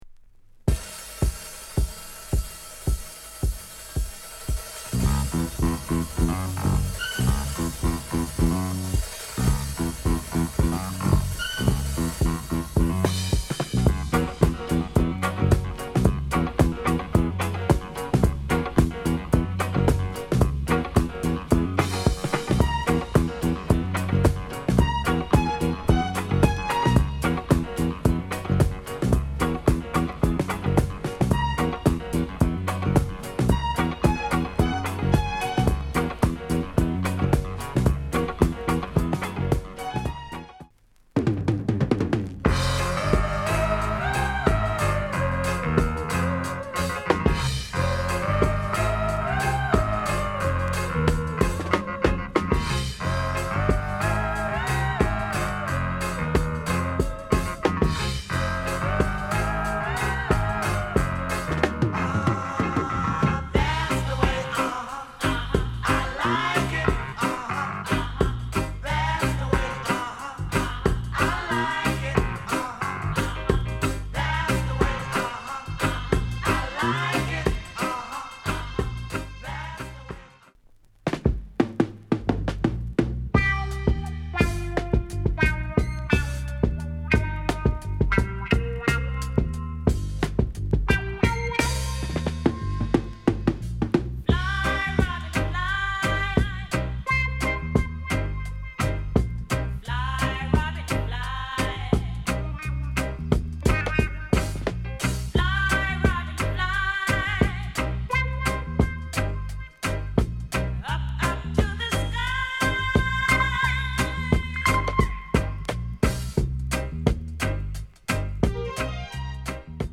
- VG�� shrink ������ RARE FUNKY REGGAE